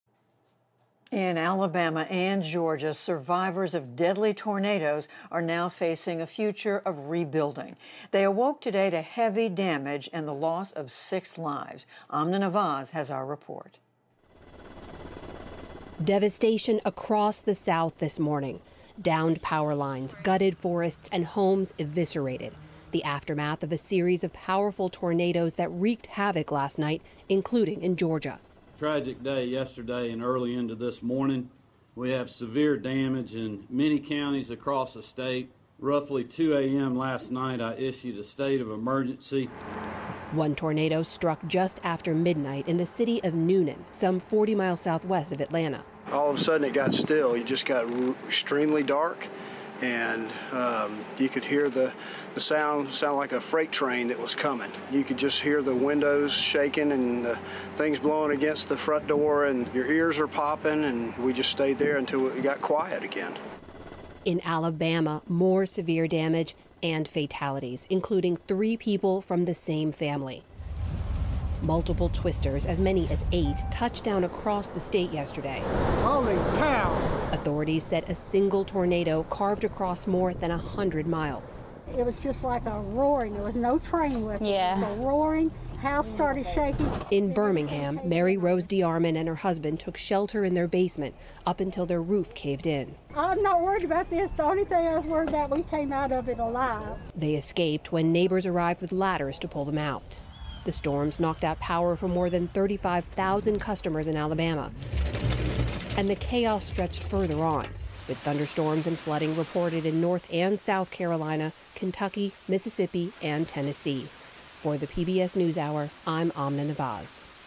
News
英语访谈节目:龙卷风袭卷佐治亚州和阿拉巴马州